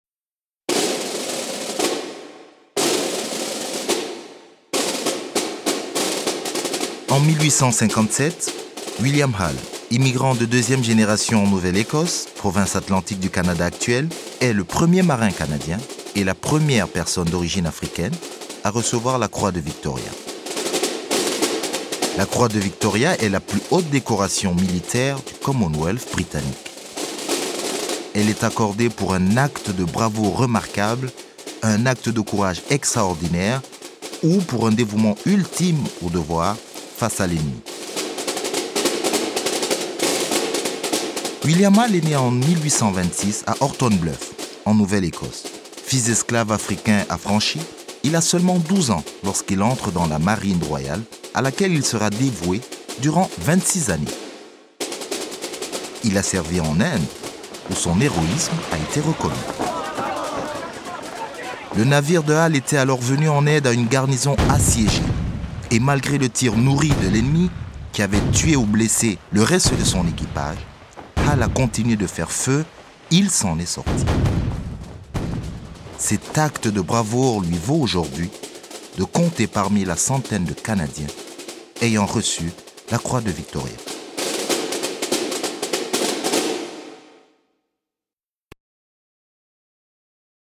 Ceremonial Drum 1 – (A. Piner) – Attic – AVF 106 CD
Narrateur: